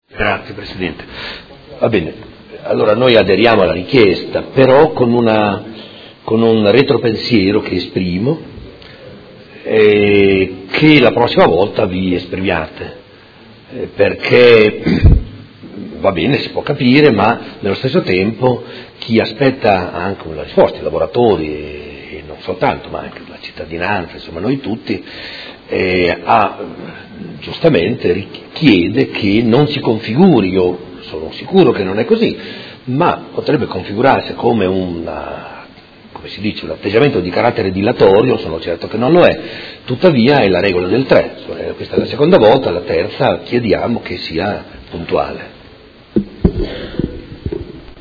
Seduta del 22/11/2018. Risponde a richiesta di rinvio a interrogazione del Consigliere Campana (Art1-MDP/Per Me Modena) avente per oggetto: Voci su una possibile vendita delle azioni di Farmacie Comunali di Modena SpA possedute da Finube